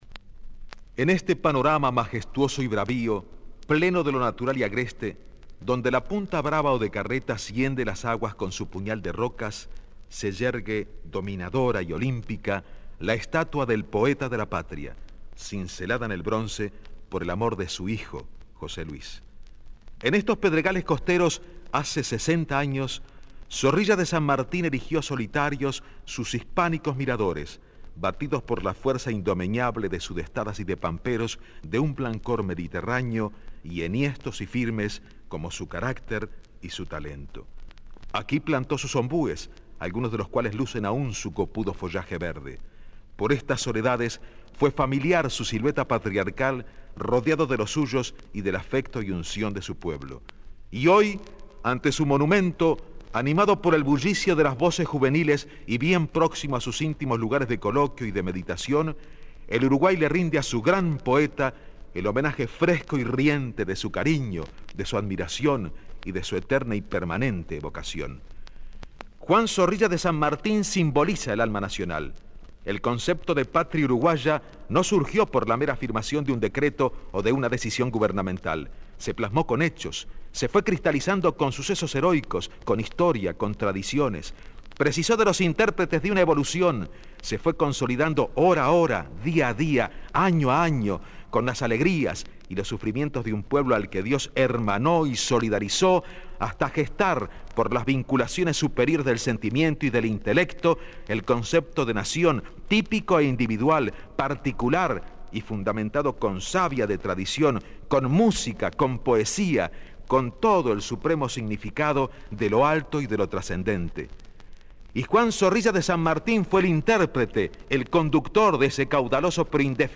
* Discurso 4/4/1976 del Prof. Martín C.Martínez Sub-Secret.MEC en Homenaje a Juan Zorrilla de San Martín